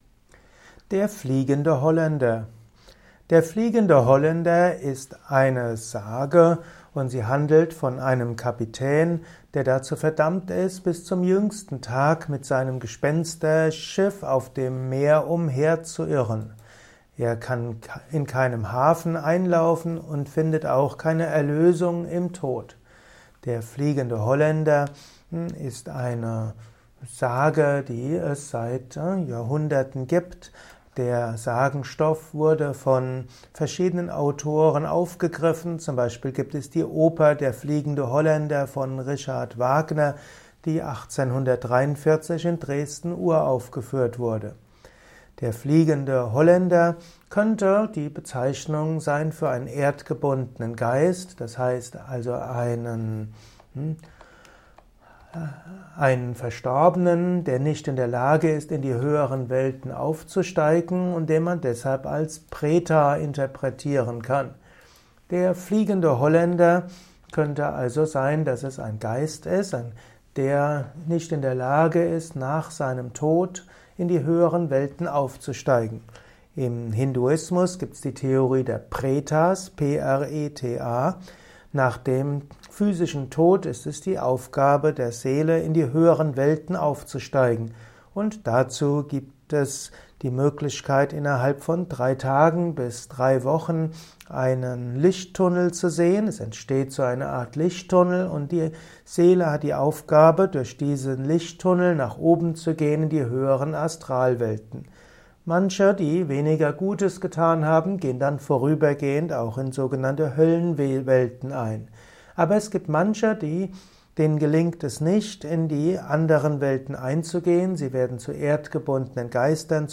Vortragsaudio rund um das Thema Der fliegende Holländer.
Dies ist die Tonspur eines Videos aus dem Youtube